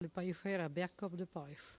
Langue Maraîchin
Patois - archive
Catégorie Locution